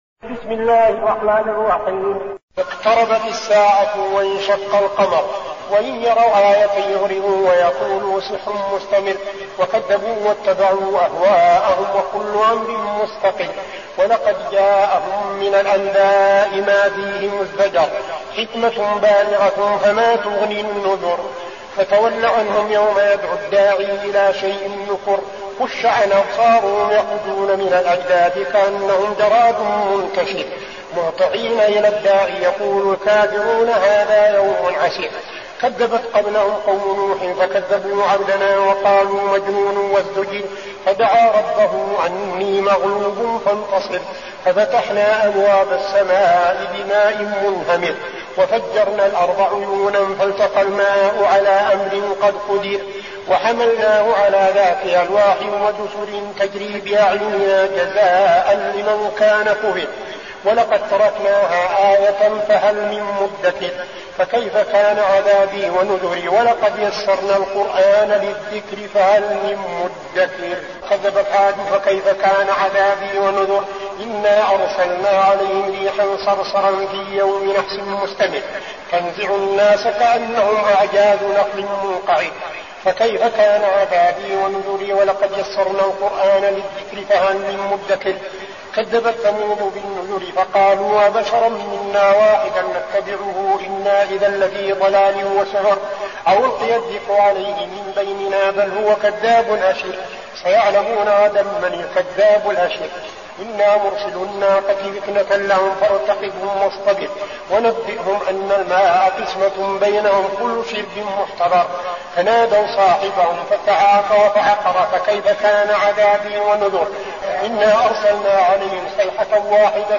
المكان: المسجد النبوي الشيخ: فضيلة الشيخ عبدالعزيز بن صالح فضيلة الشيخ عبدالعزيز بن صالح القمر